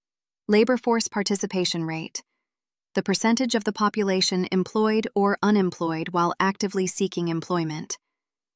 Listen to the terms you’ll need to remember most with an audio reading of definitions while you think through them.